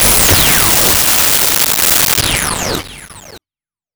Laser3
laser3.wav